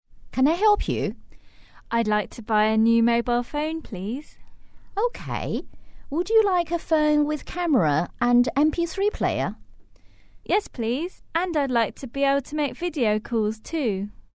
英语初学者口语对话第63集：我想买个带照相机和MP3的手机